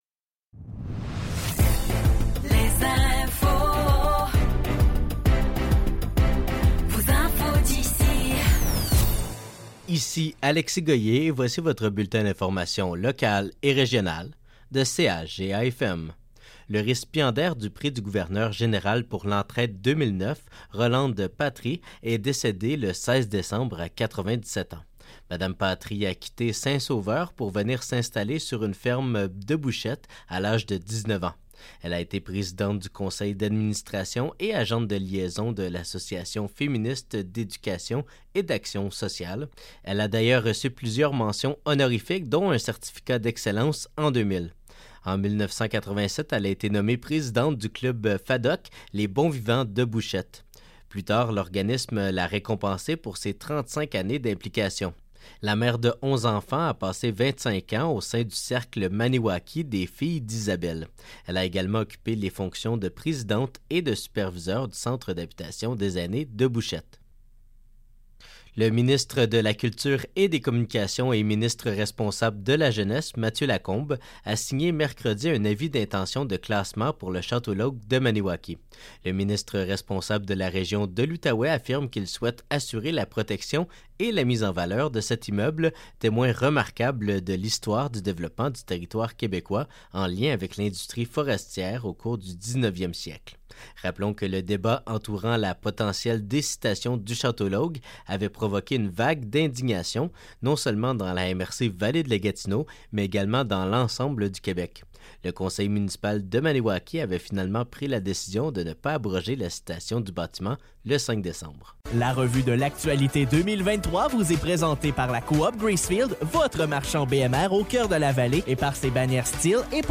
Nouvelles locales - 26 décembre 2023 - 16 h